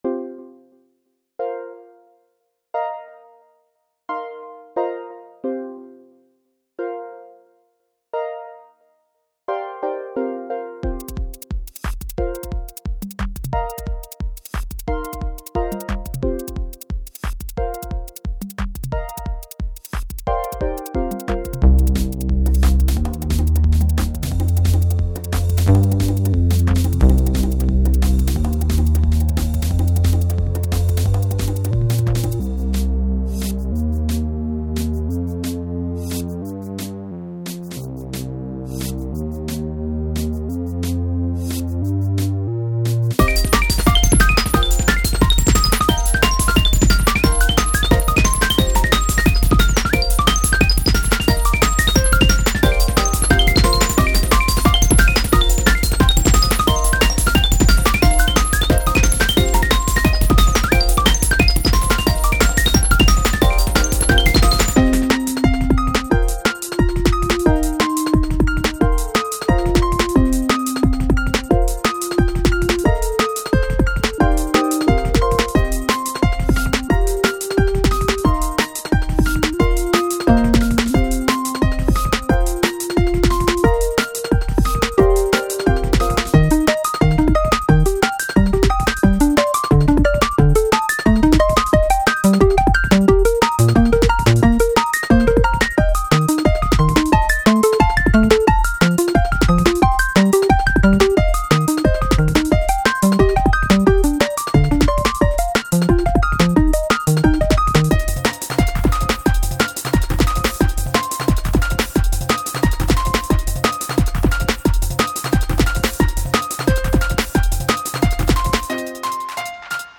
とゆうわけでアッパーチューンを１曲icon_japanesetea.gif